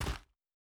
Land Step Gravel A.wav